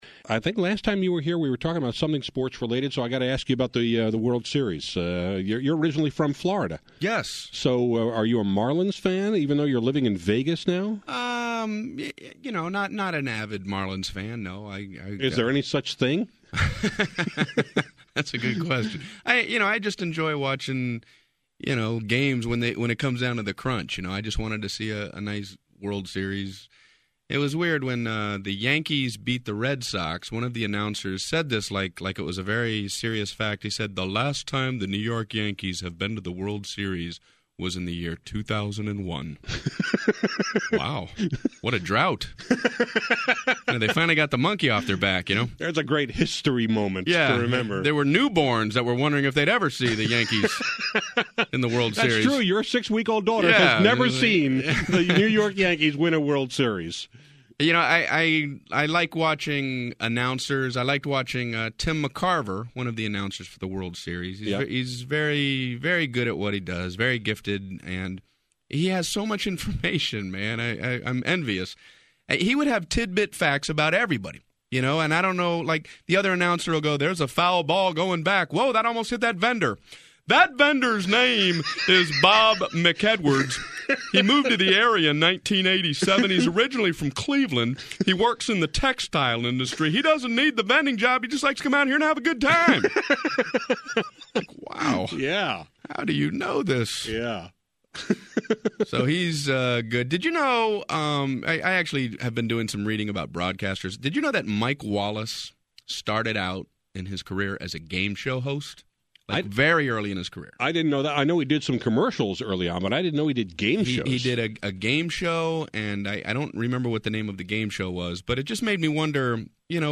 Brian Regan, one of the top comedians in the country and frequent guest on my show, was back today to joke about Little League baseball, softball, the election, and the instructions on a box of Pop-Tarts.